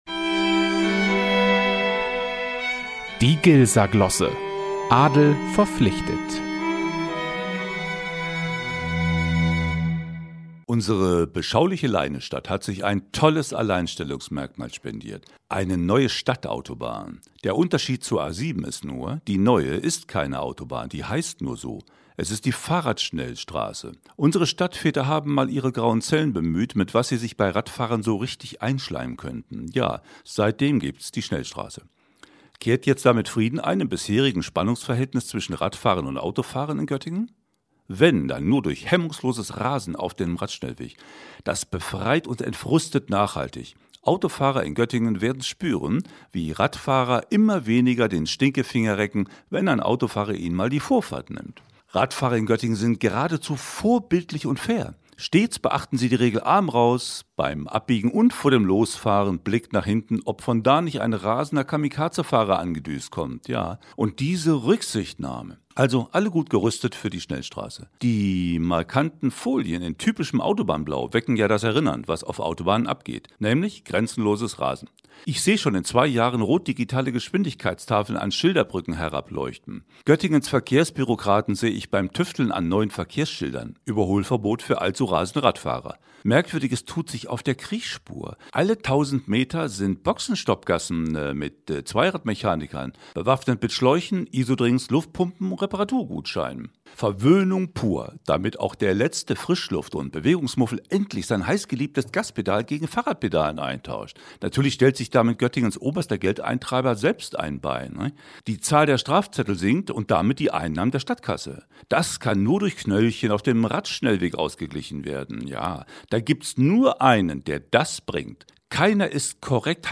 18_Glosse.mp3